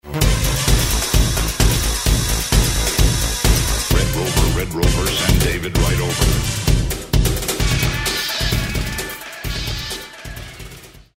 The panned brass sound that appears after the break